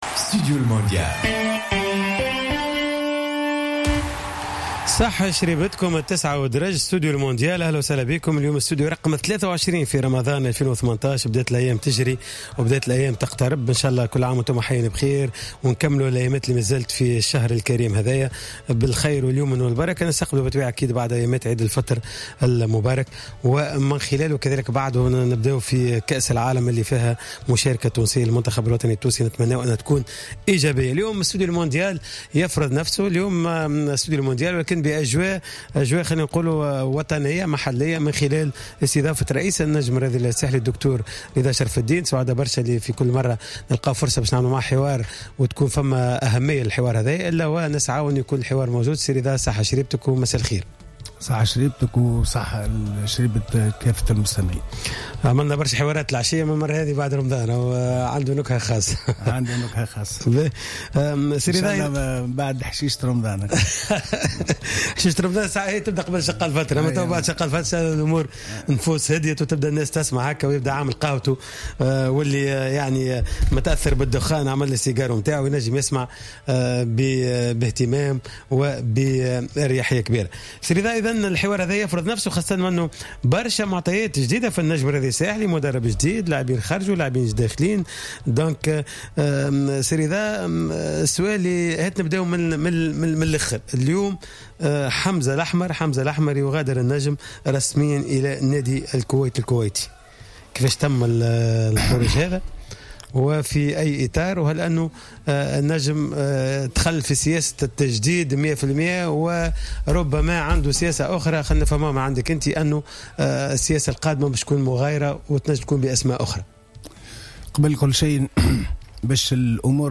نزل رئيس النجم الساحلي رضا شرف الدين ضيفا على حصة خاصة من برنامج ستوديو المونديال للحديث حول عديد الأمور التي تخص النادي.